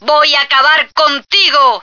flak_m/sounds/female1/est/F1diebitch.ogg at 9e43bf8b8b72e4d1bdb10b178f911b1f5fce2398